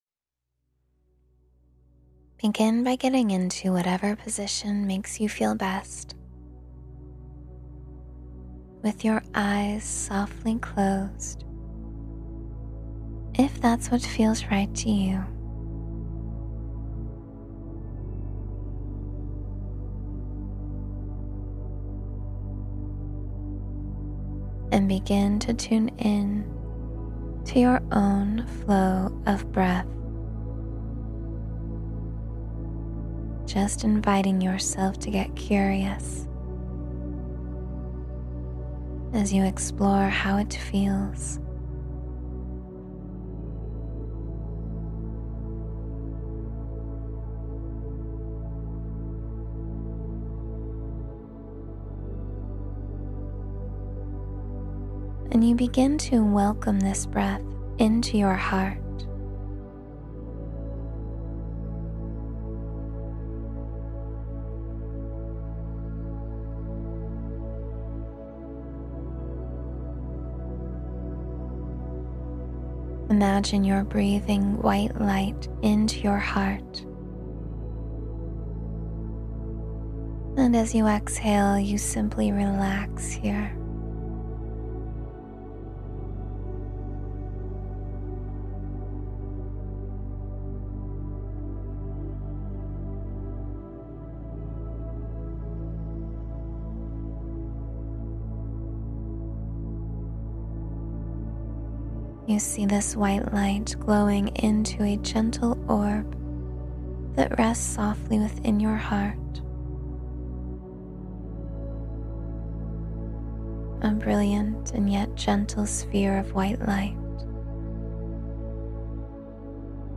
Cleanse and Refresh Your Energy in 15 Minutes — Meditation for Quick Rejuvenation